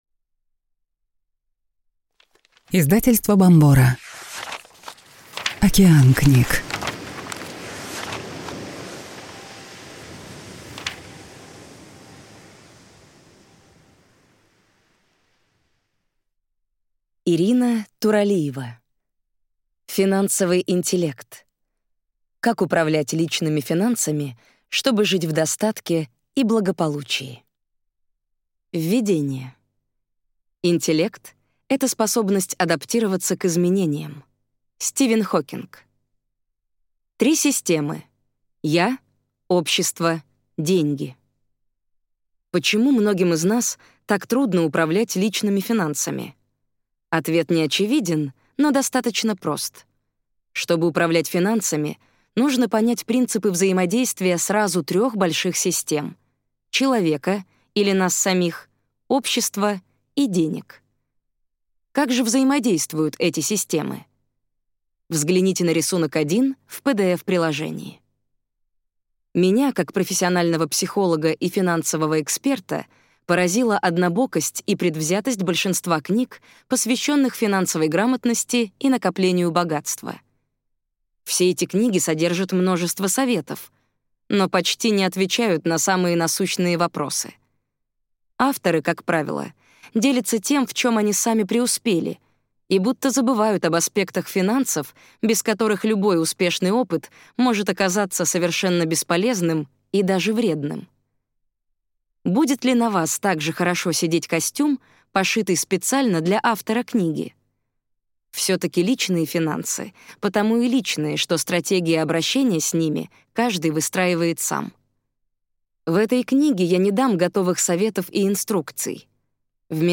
Аудиокнига Финансовый интеллект.